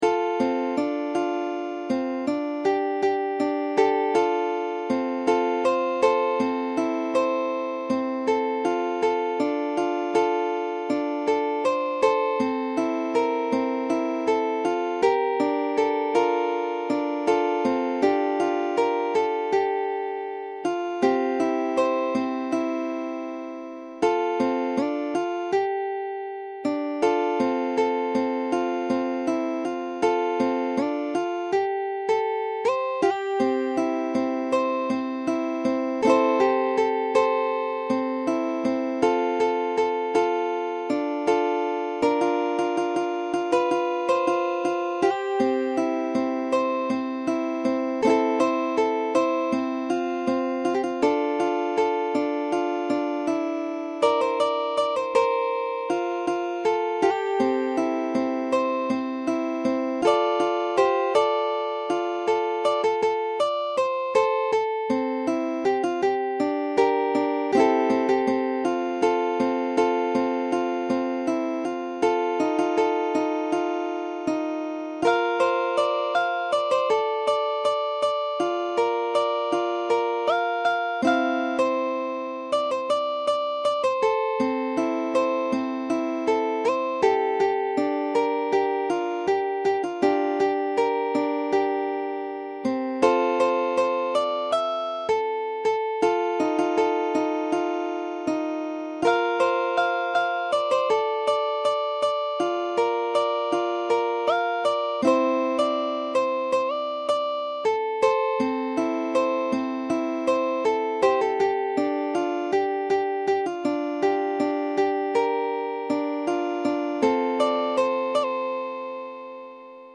Ukulele Fingerstyle Solo Tab 乌克丽丽 指弹 独奏 谱